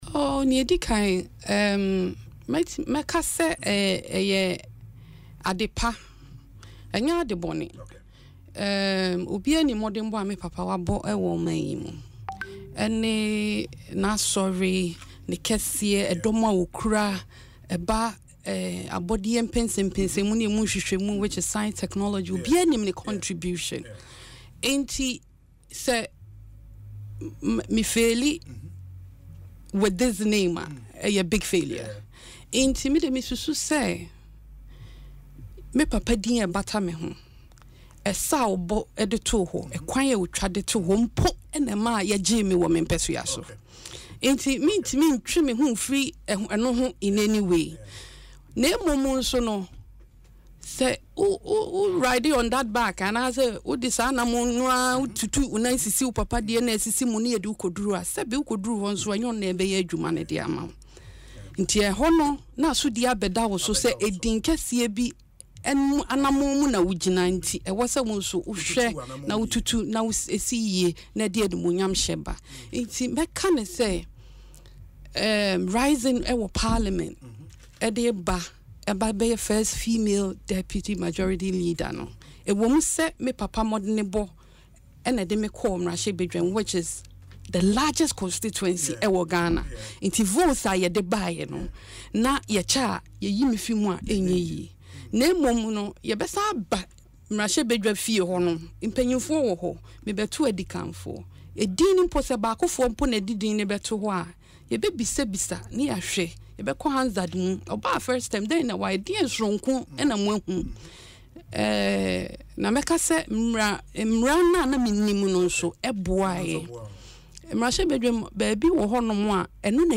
The former Gender Minister disclosed this in an interview on Asempa FM’s Ekosii Sen, stating that her father’s name comes with a bigger responsibility.